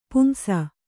♪ pumsa